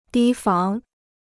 提防 (dī fang) Free Chinese Dictionary